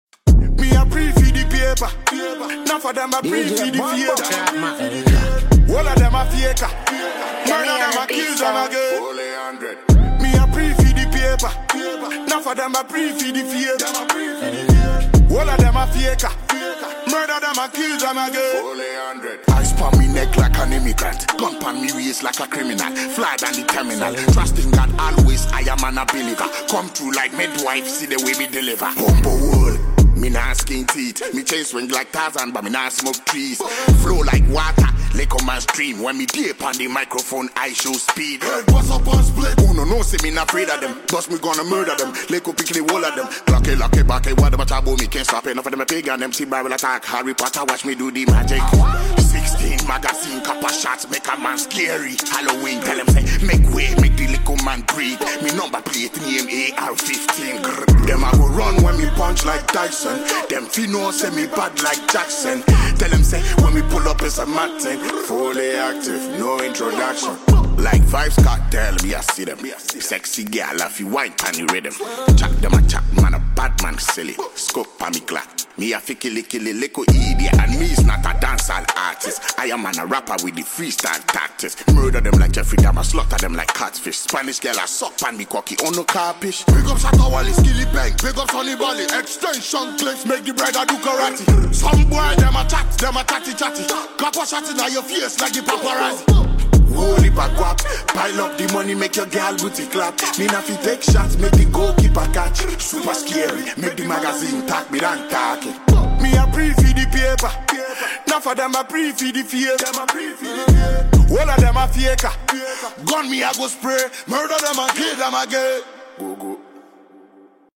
is a high-energy dancehall/hip-hop track
The track exudes energy, party vibes, and lyrical intensity.
• Genre: Dancehall / Hip-Hop